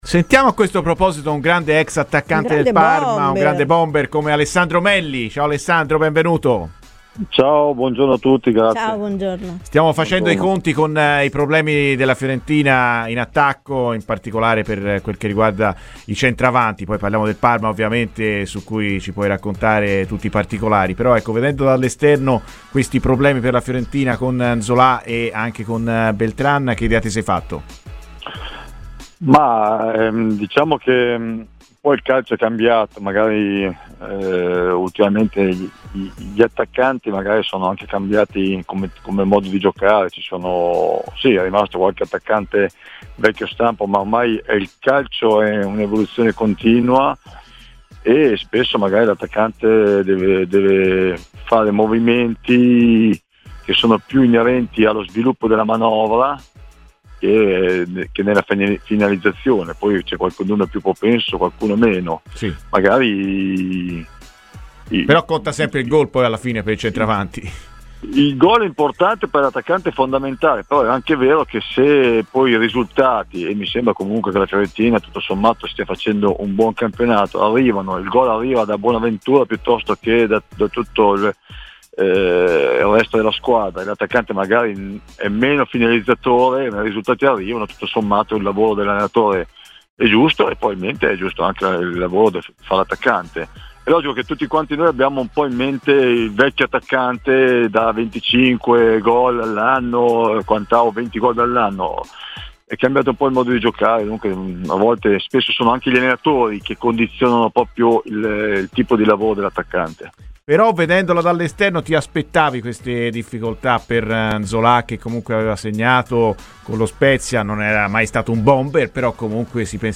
Alessando Melli, ex calciatore del Parma, è intervenuto ai microfoni di Radio FirenzeViola nel corso della trasmissione "Viola Amore Mio".